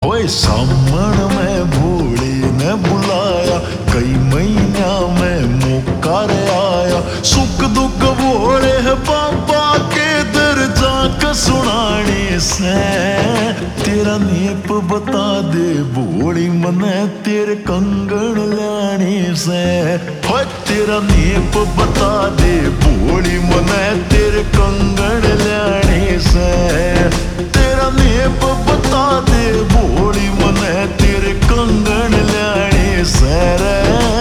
Haryanvi Songs
Slow Reverb Version
• Simple and Lofi sound
• Crisp and clear sound